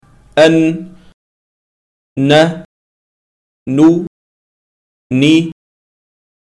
5. Näspassagen ( الخَيشُوم )
Från näshålan kommer ghunnah ( غُنّة ) som är det nasala ljud som finns med när vi uttalar bokstäverna mīm ( م ) och nūn ( ن ) i alla deras fall, förutom att dess längd skiljer sig från deras status, vilket kommer att diskuteras i kapitlet om nasaliseringarna (ghunnah)s.